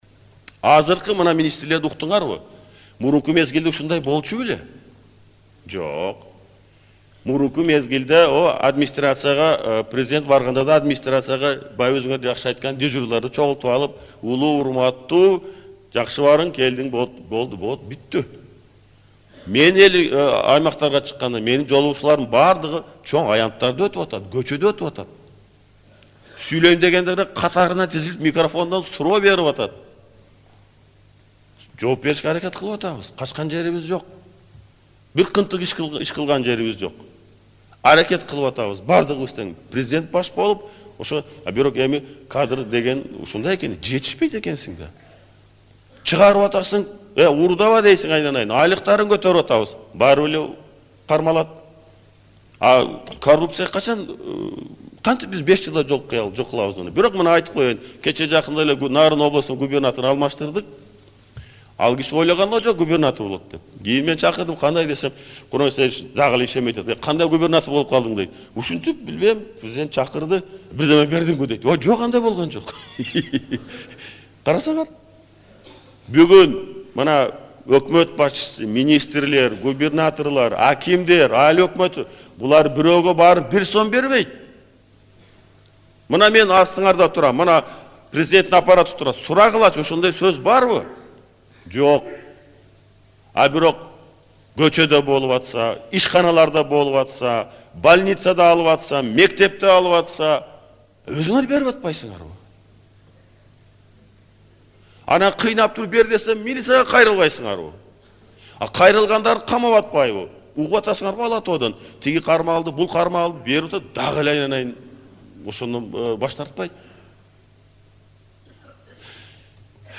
Эки күнгө созулган Ынтымак курултайынын соңунда Президент Курманбек Бакиев корутунду сөз сүйлөдү.